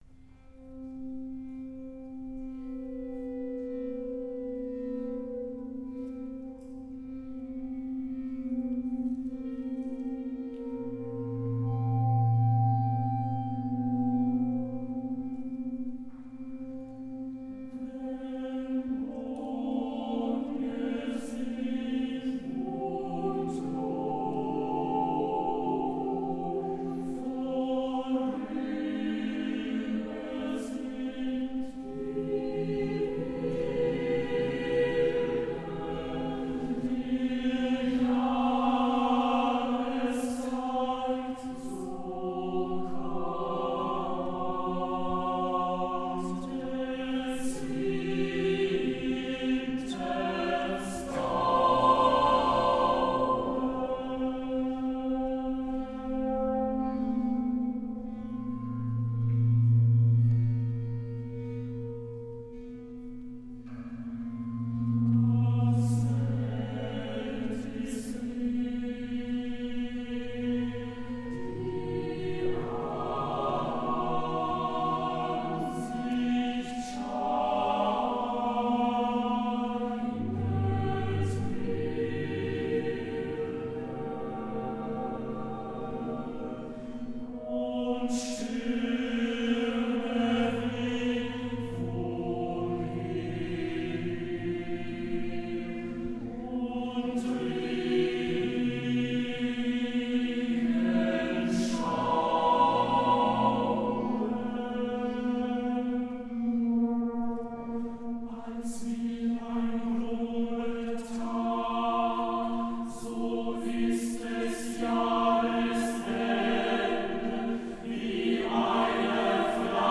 Kammermusik
für gemischten Chor und vier Stahlcelli